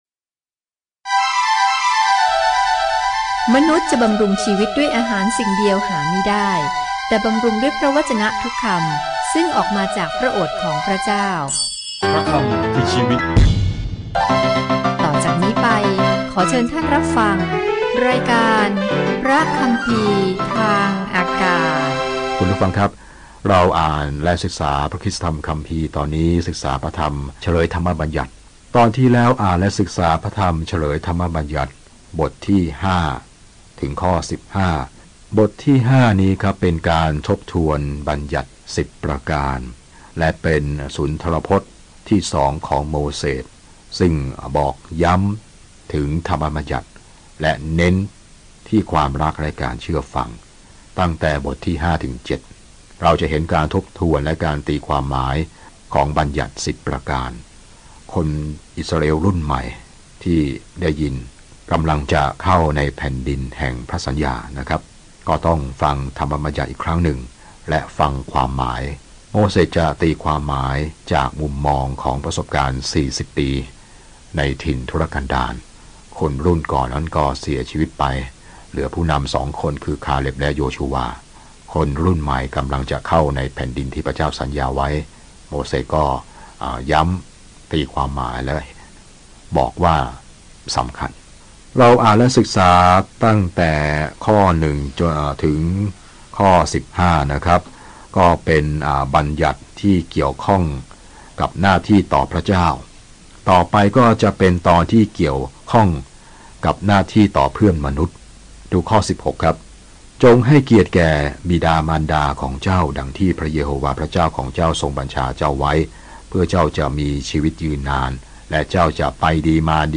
เฉลยธรรมบัญญัติสรุปกฎหมายอันดีของพระเจ้าและสอนว่าการเชื่อฟังเป็นการตอบสนองต่อความรักของพระองค์ เดินทางทุกวันผ่านเฉลยธรรมบัญญัติในขณะที่คุณฟังการศึกษาด้วยเสียงและอ่านข้อที่เลือกจากพระวจนะของพระเจ้า